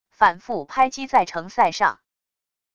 反复拍击在城塞上wav音频